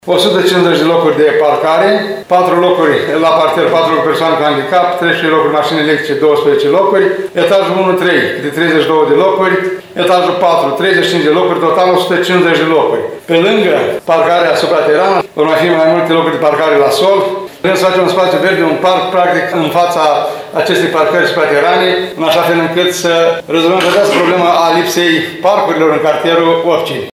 Primarul ION LUNGU a detaliat astăzi investiția, aflată în prezent la stadiul de documentație.